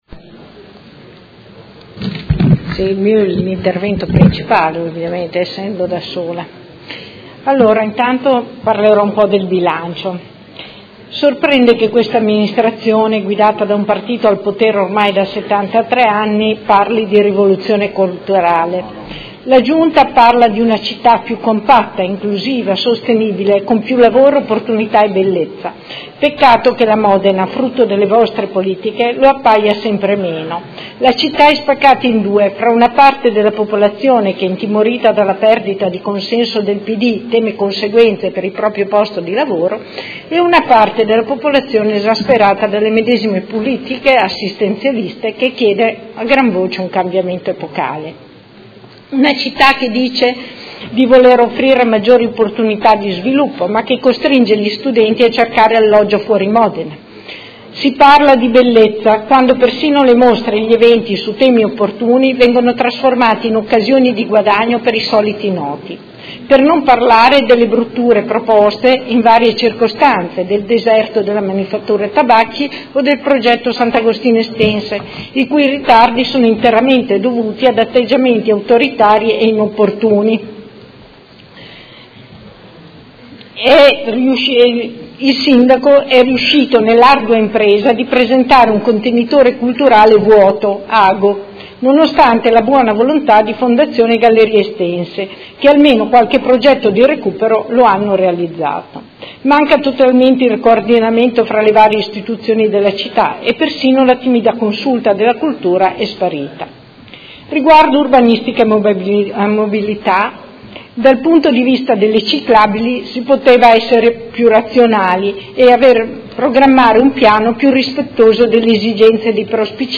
Seduta del 20/12/2018. Dibattito su delibera di bilancio, Ordini del Giorno, Mozioni ed emendamenti